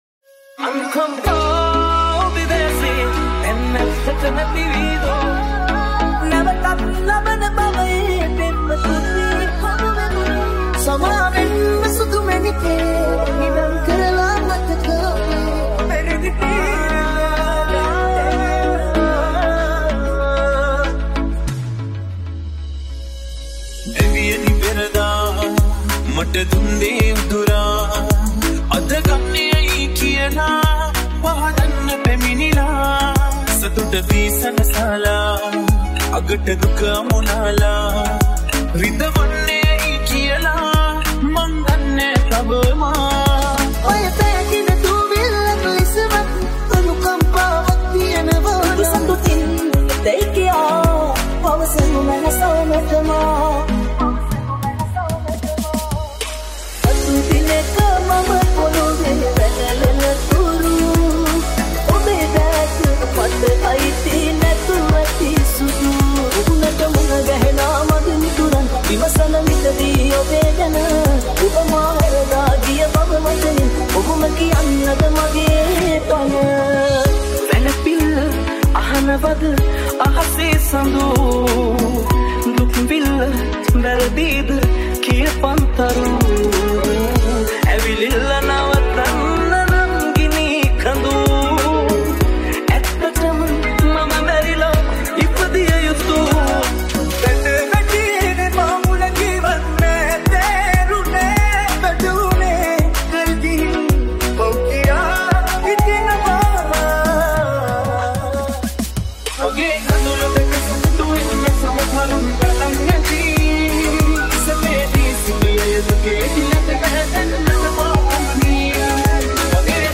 Sinhala Remix Songs | Romantic Mashup | Sinhala DJ Songs